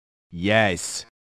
Вы найдете разные варианты: от радостного и энергичного до томного и шепотного «yes», записанные мужскими, женскими и детскими голосами.